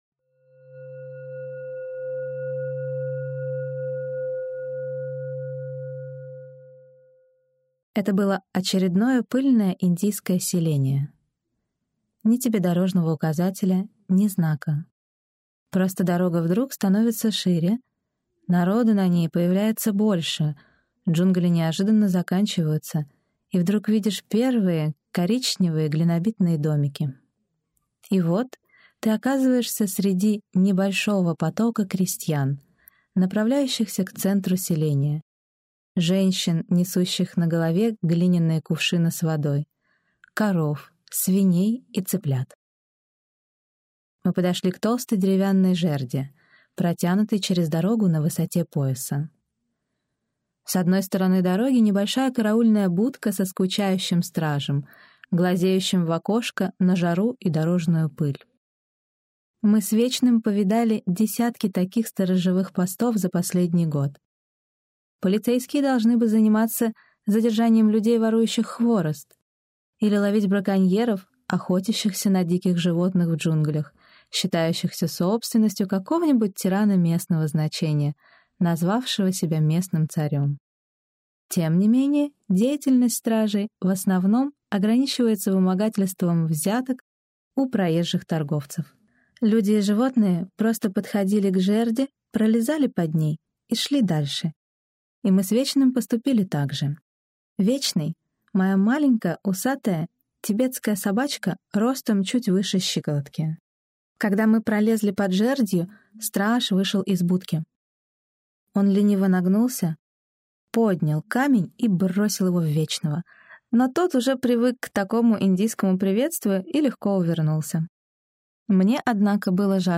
Аудиокнига Как работает йога: здоровье по системе Алмазного Огранщика | Библиотека аудиокниг